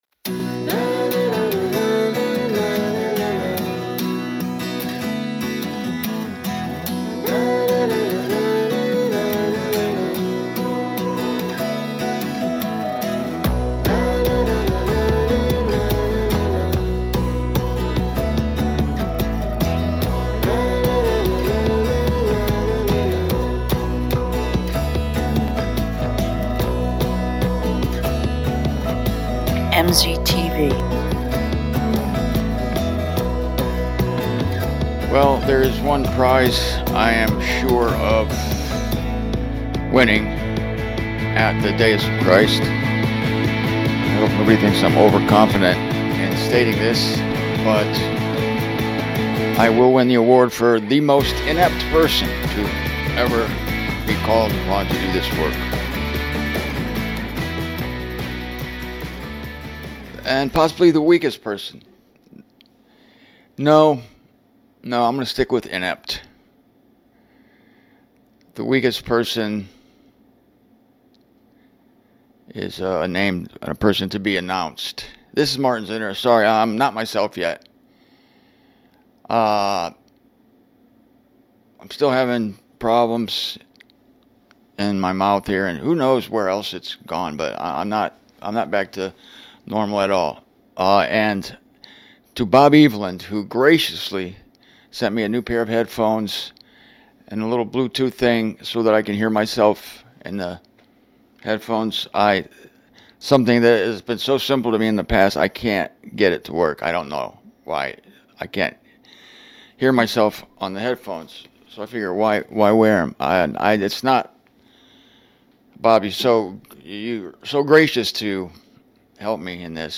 I tried setting up the studio today, but this was the best I could do. It basically sucks but I fixed it with editing tricks.